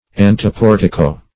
Anteportico \An`te*por"ti*co\, n. An outer porch or vestibule.